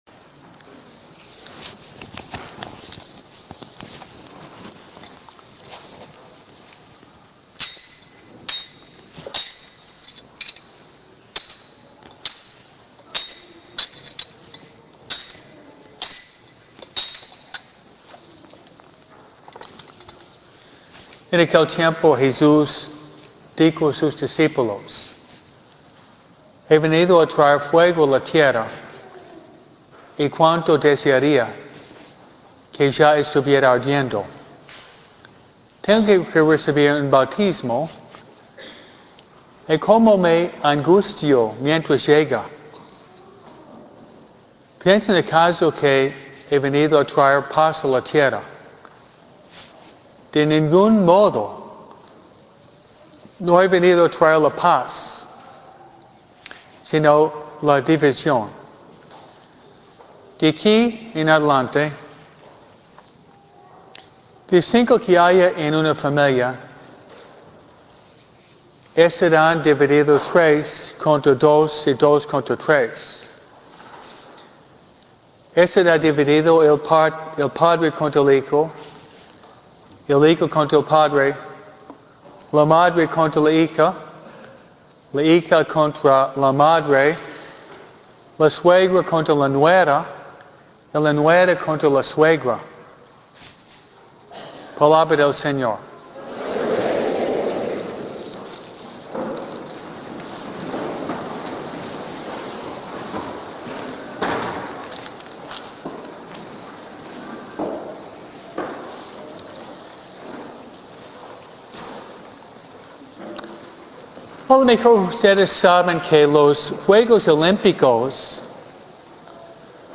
MISA – ATLETAS POR CRISTO